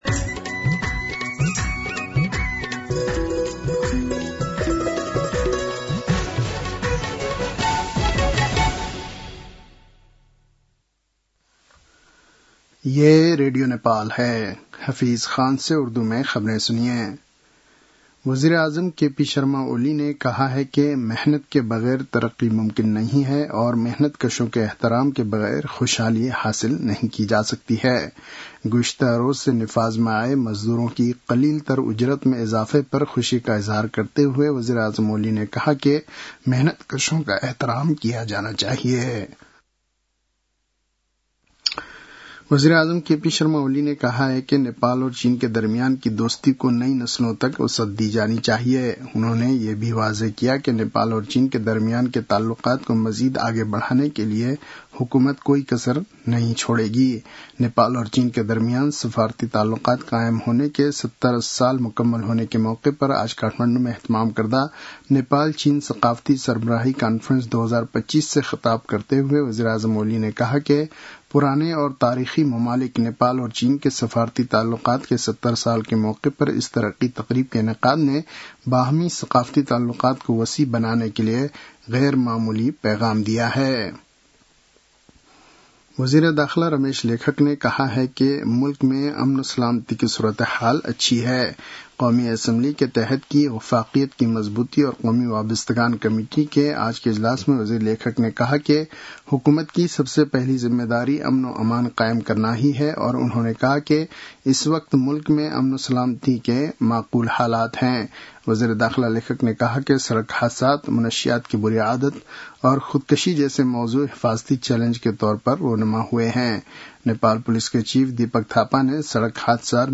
उर्दु भाषामा समाचार : २ साउन , २०८२